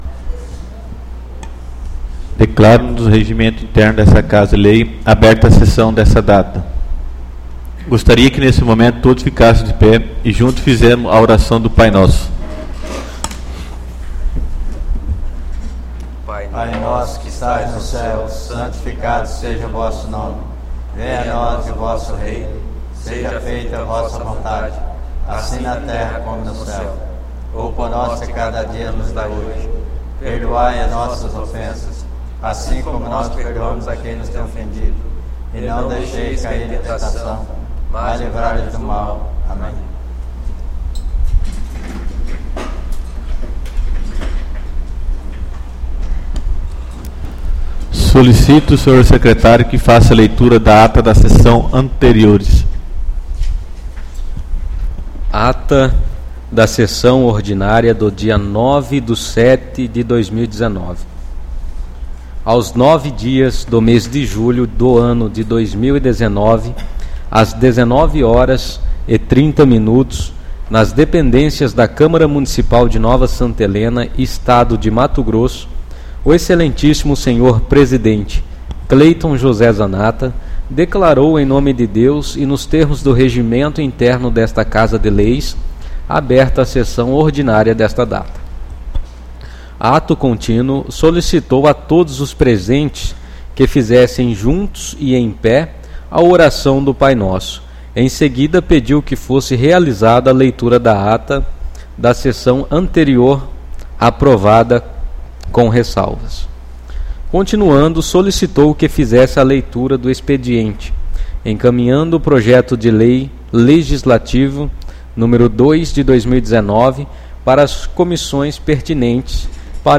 ÁUDIO DA SESSÃO ORDINÁRIA 16/07/2019